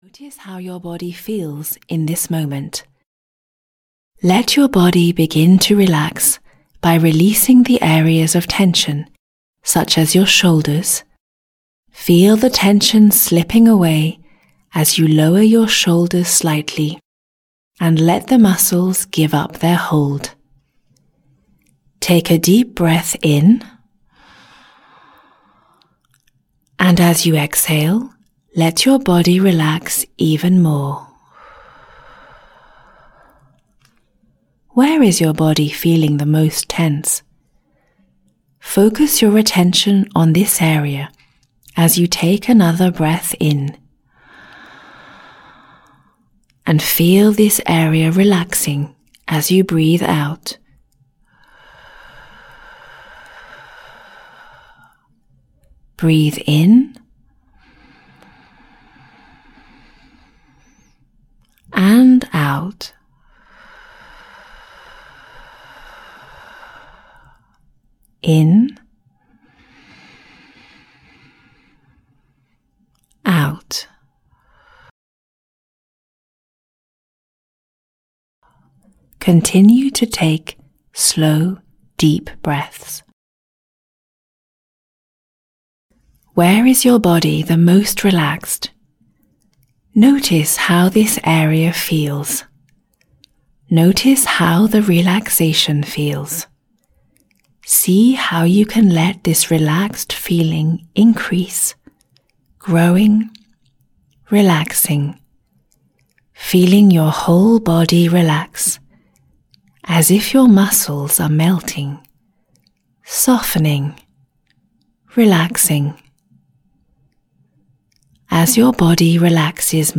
10 Simple Relaxation Exercises (EN) audiokniha
Ukázka z knihy